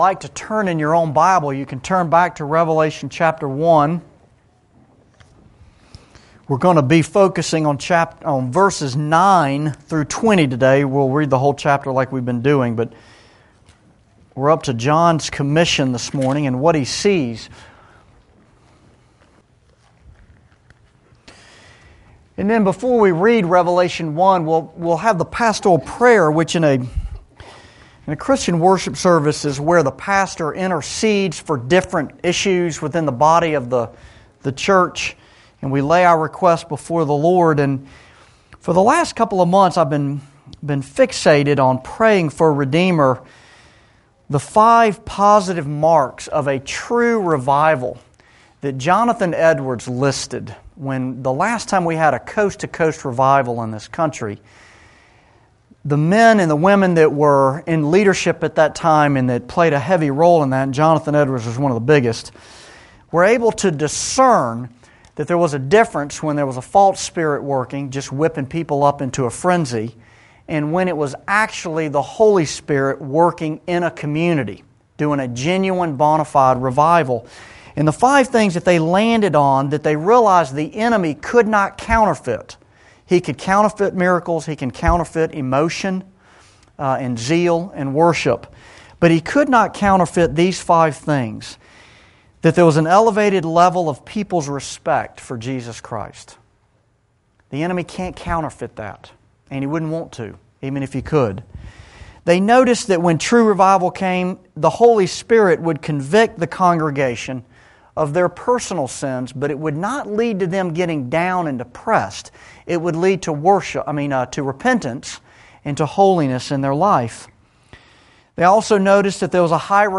Revelation Sermon 3